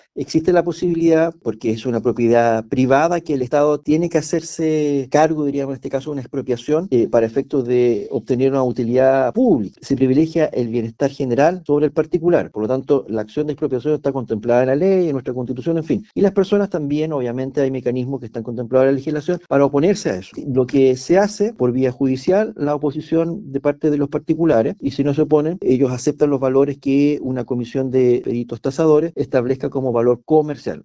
Así las cosas, una de las mayores inquietudes son los valores que recibirá cada persona, en ese sentido, el seremi de Obras Públicas, Juan Alvarado, explicó que se ofrecerá el valor comercial.